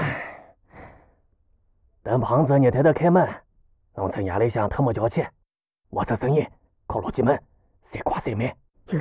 c02_6偷听对话_癞子_7_fx.wav